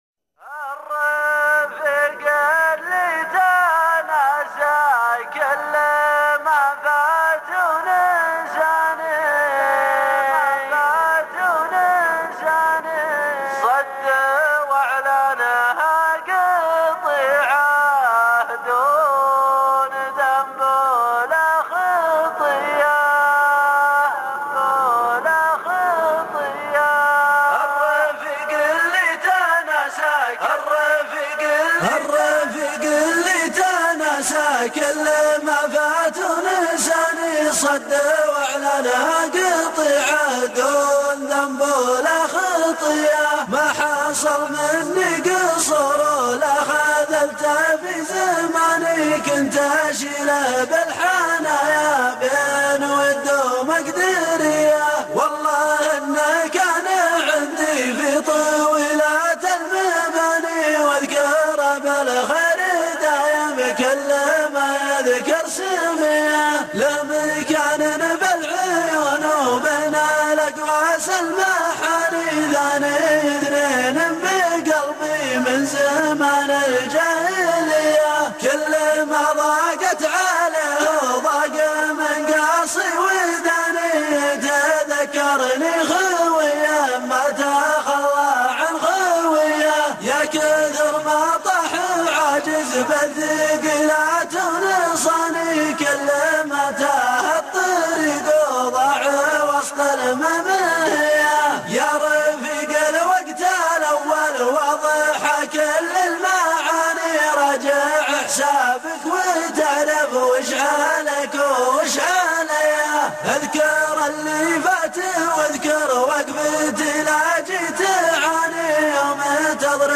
الشيلات -> شيلات منوعه 7